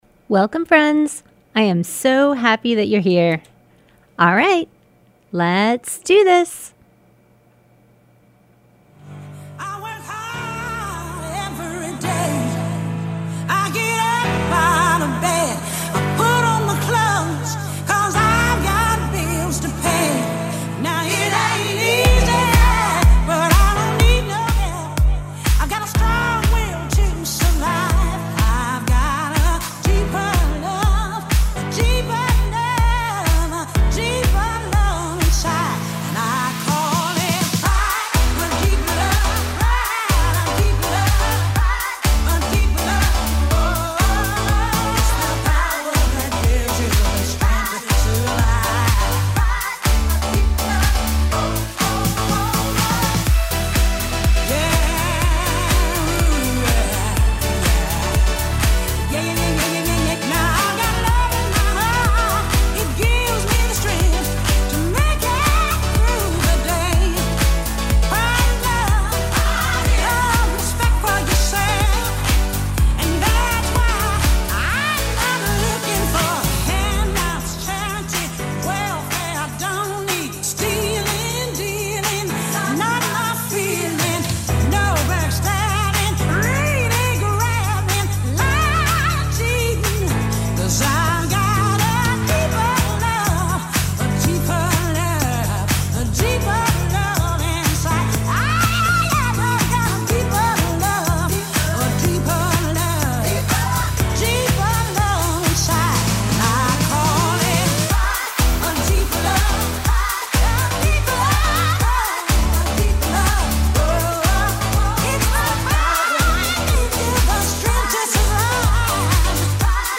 Broadcast every Thursday night from 6:30 to 8pm on WTBR